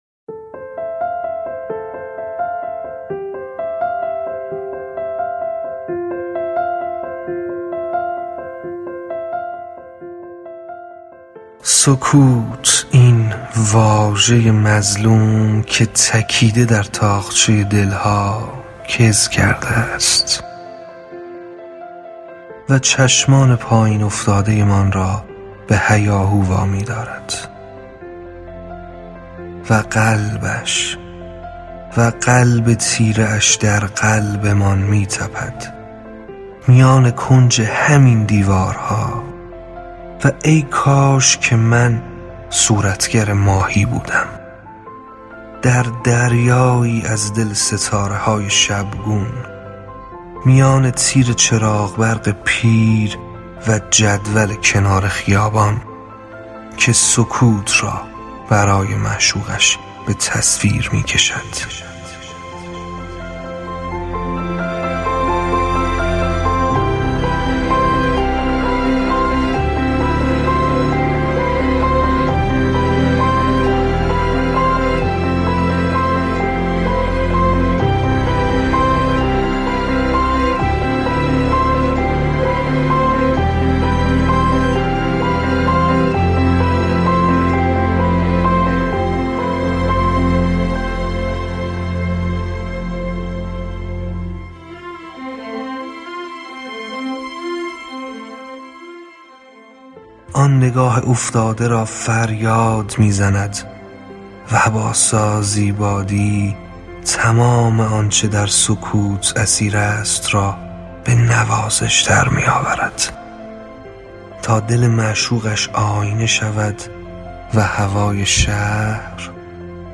میکس و مسترینگ